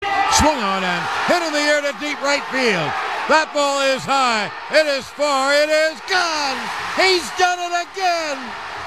john-sterling-calls-raul-ibanez-game-tying-home-run-from-game-1-2012-alcs-audiotrimmer.mp3